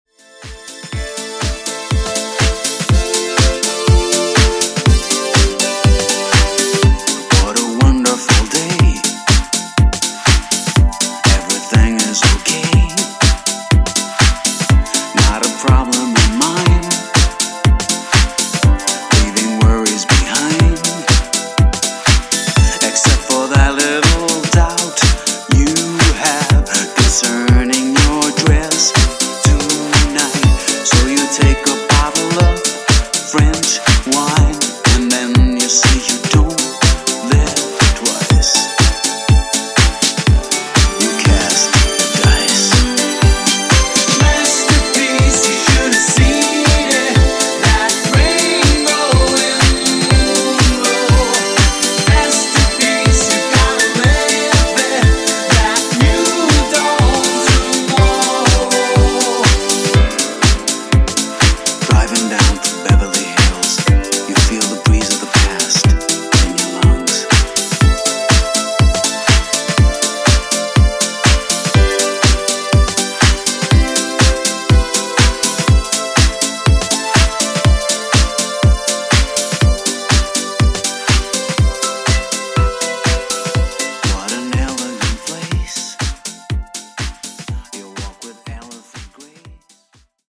80s Disco House)Date Added